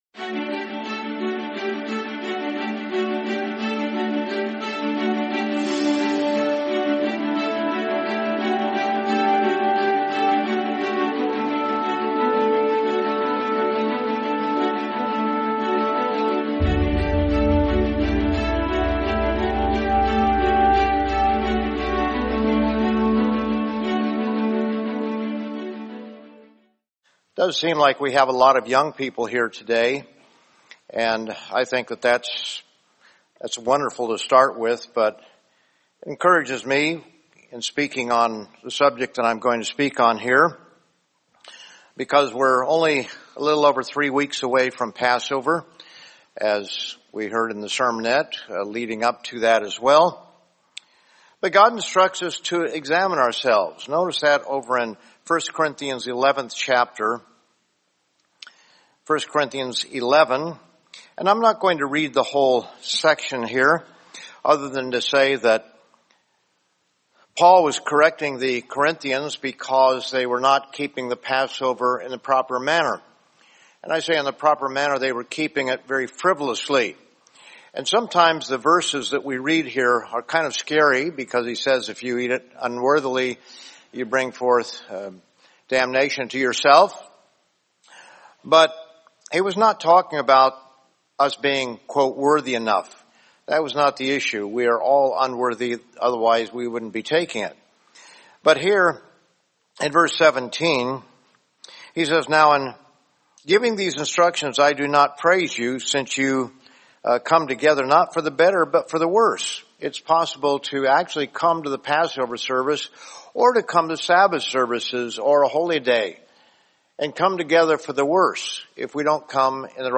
Sermon Passover and Unleavened Bread Preparation